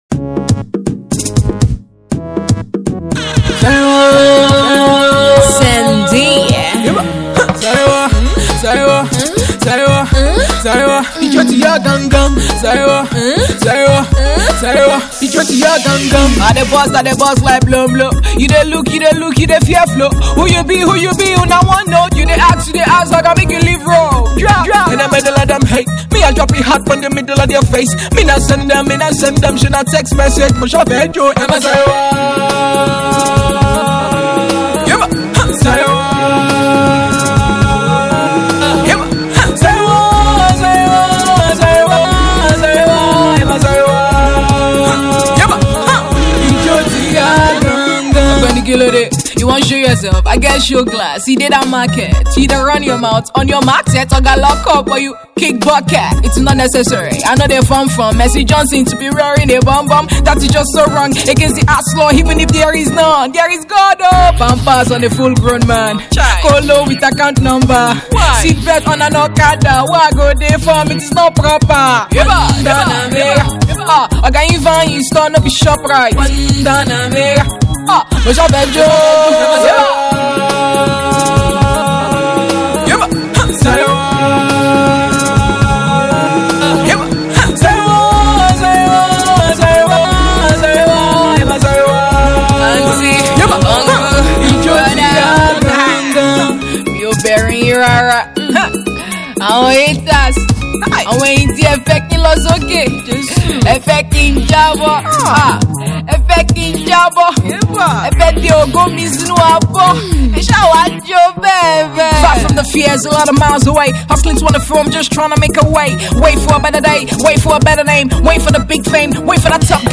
blends in a feel of reggae